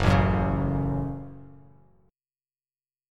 Gbm Chord
Listen to Gbm strummed